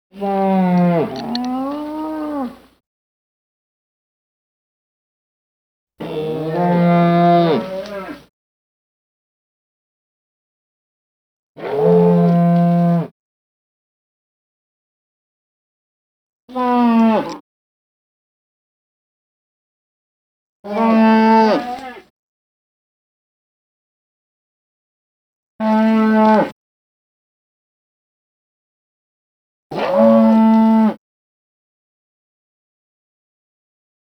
animal
Moose Moans and Calls